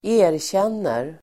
Uttal: [²'e:rtjen:er el. ²'ä:-]